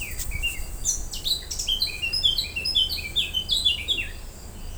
Vogelstimmen: Zaunkönig,
Mönchsgrasmücke,
moenchsgrasmuecke.wav